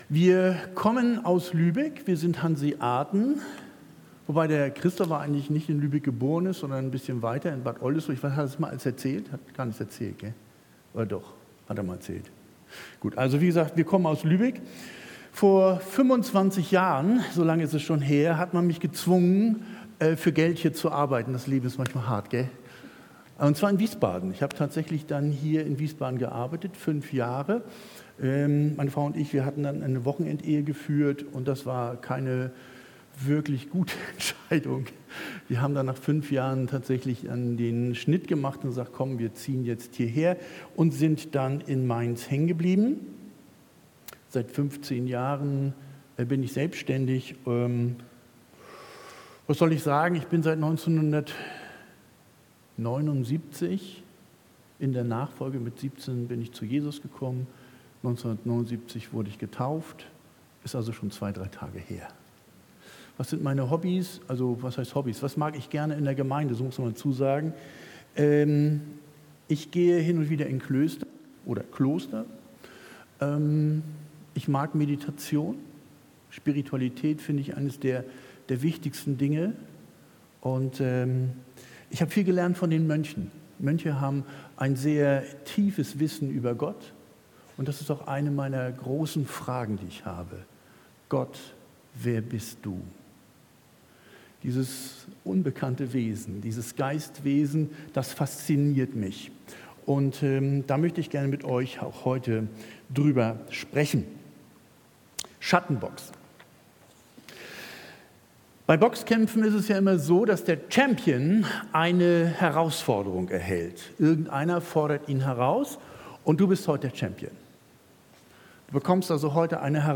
Predigt Schattenboxen 1.Mose 12,8 Sonntag, 9.